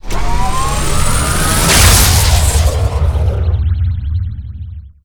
starprobelaunch.ogg